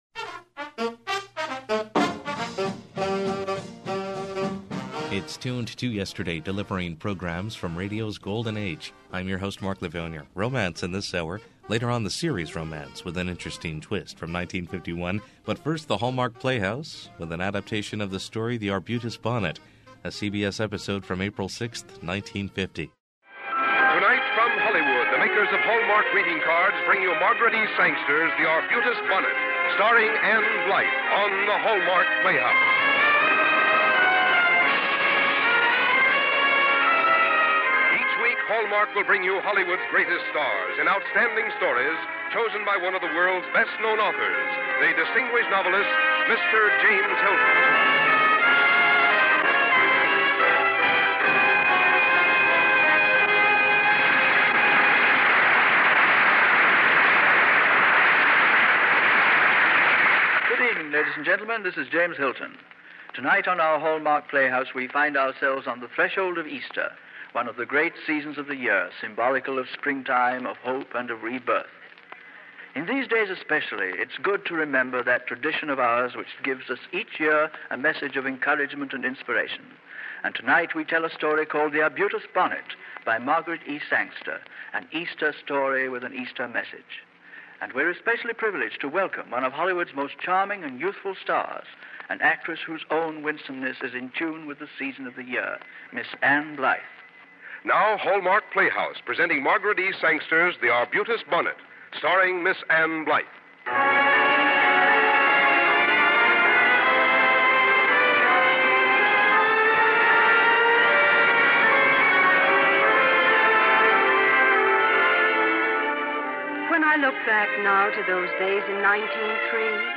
The highest quality broadcasts are restored and played as they were heard years and years ago.
Audio Drama